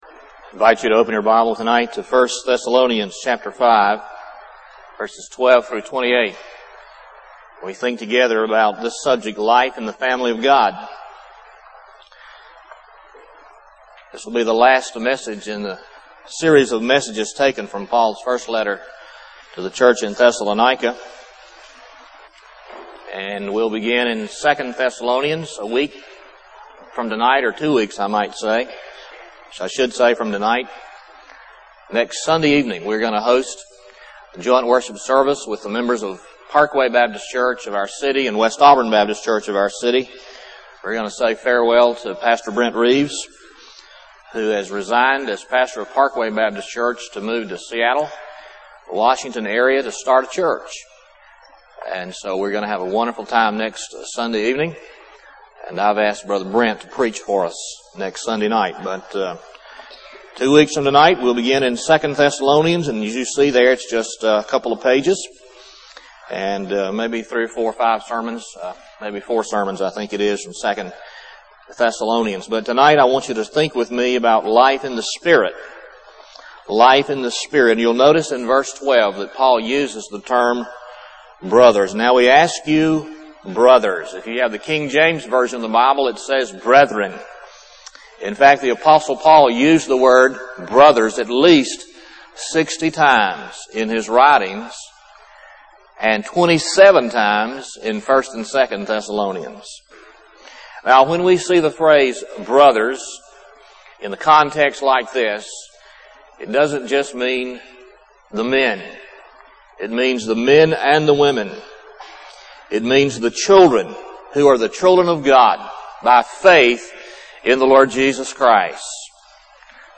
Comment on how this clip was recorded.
Lakeview Baptist Church - Auburn, Alabama